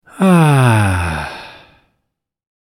Satisfied Ahh Sound Effect
Satisfied-ahh-sound-effect.mp3